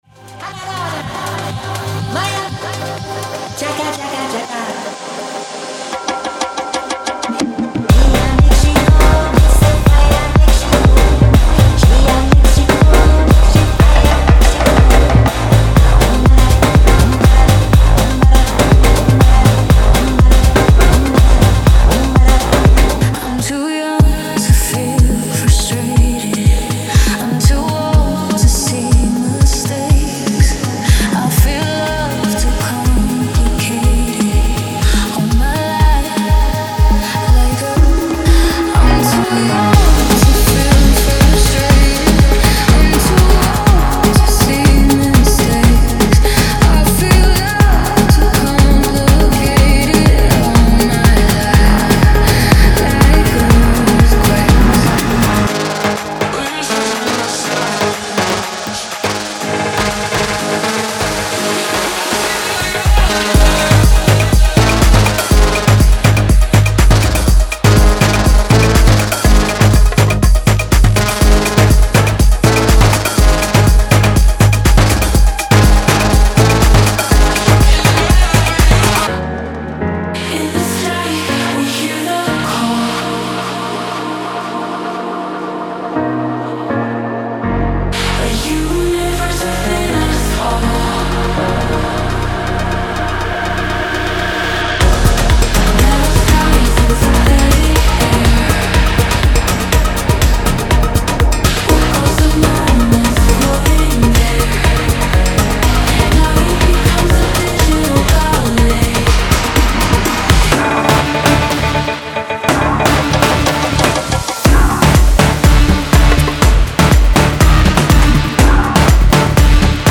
Tribal
95 Drum & Percussion Loops
69 Instrumental Loops (Basses, Leads, Synths)
12 Piano Loops